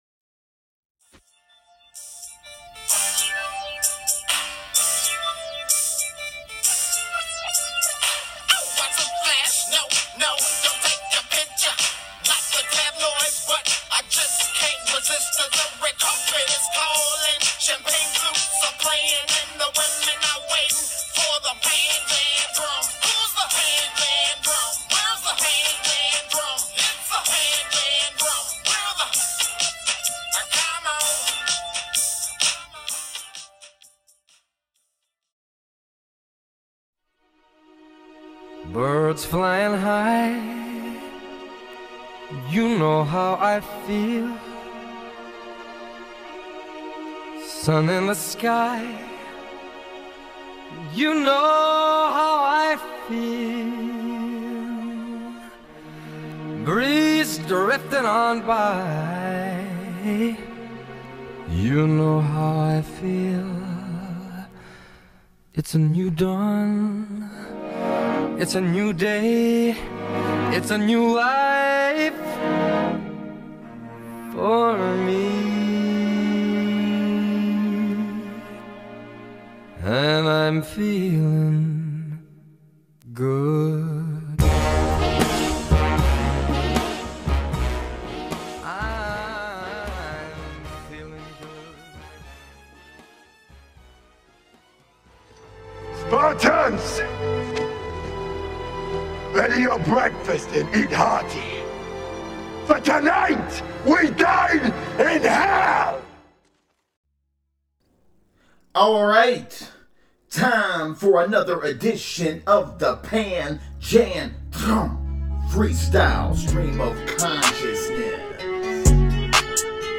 The Panjandrum is a Series of Freestyle Stream of Consciousness recorded Uncut, Unscripted & Raw — with No Notes — with the aim of Challenging, Disrupting, Motivating & Inspiring via Organic Enthusiastic Content.